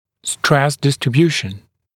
[stres ˌdɪstrɪ’bjuːʃn][стрэс ˌдистри’бйу:шн]распределение давления. нагрузки